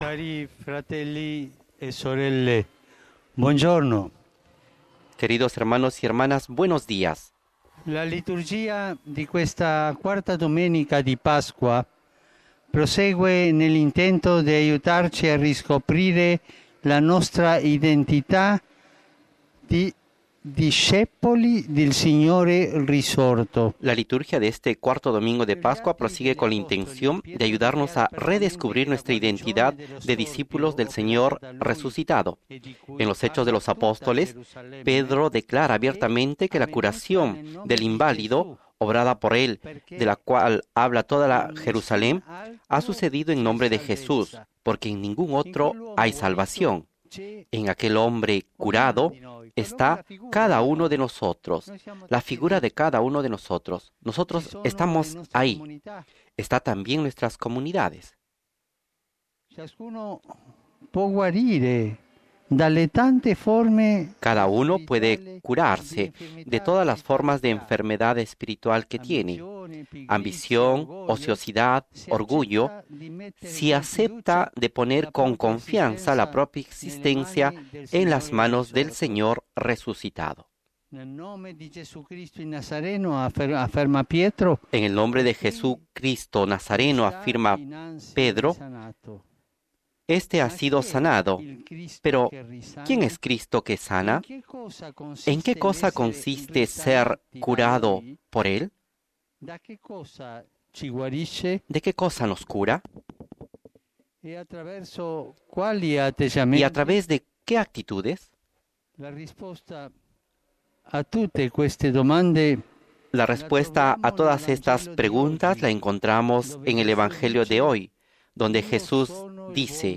Tras ordenar a 16 nuevos sacerdotes en una misa en la Basílica de San Pedro, en el IV Domingo de Pascua, fiesta del Buen Pastor y Jornada Mundial de oración por las vocaciones; el Papa Francisco rezó la oración mariana del Regina Coeli, desde la ventana del Palacio Apostólico.
“¿Pero quién es Cristo sanador? ¿En qué consiste ser sanado por él? ¿De qué nos cura? ¿Y mediante qué maneras?”, se preguntó el Obispo de Roma ante miles de fieles y peregrinos congregados en la Plaza de San Pedro.